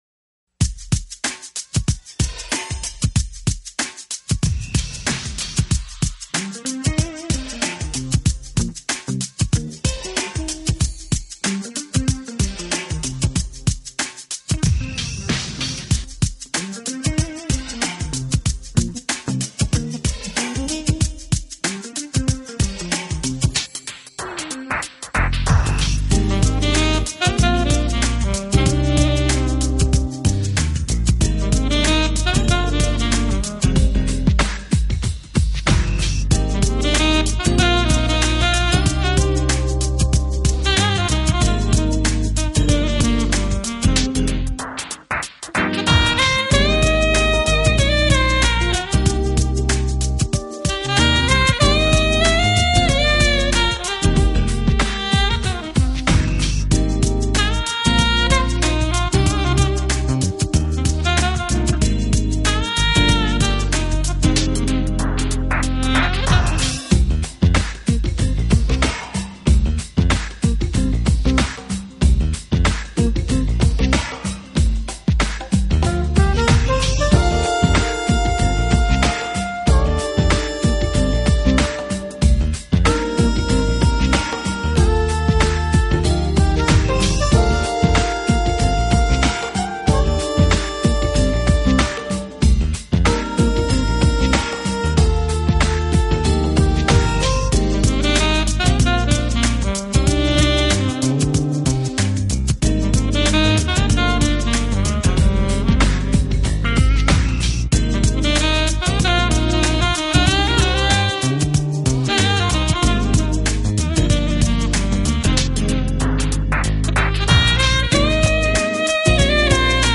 热力四射，控制高低音收发自如，旋律盘旋错落，每个音符都可以令人惊讶，在本张专辑中